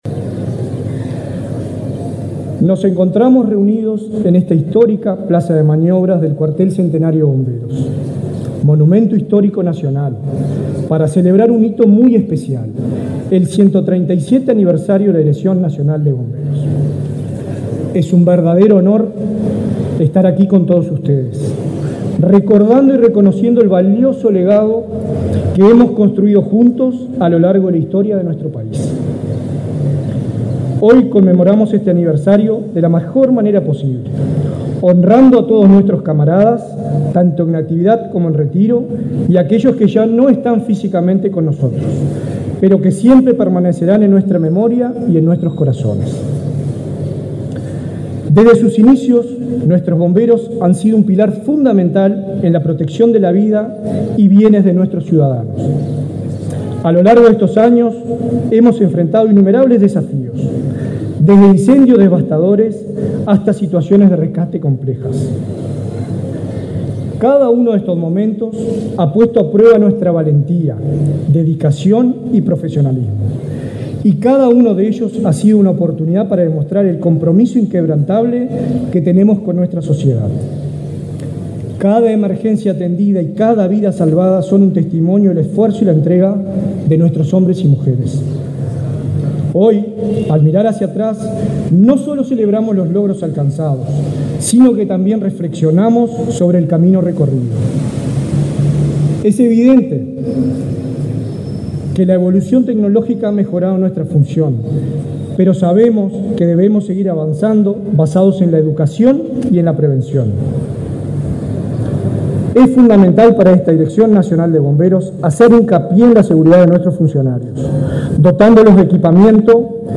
Palabras de autoridades en aniversario de la Dirección Nacional de Bomberos
Palabras de autoridades en aniversario de la Dirección Nacional de Bomberos 24/10/2024 Compartir Facebook X Copiar enlace WhatsApp LinkedIn El titular de la Dirección Nacional de Bomberos, Richard Barboza, y el subsecretario del Ministerio del Interior, Pablo Abdala, encabezaron la ceremonia del 137.° aniversario de la creación del mencionado servicio, dependiente de la referida cartera.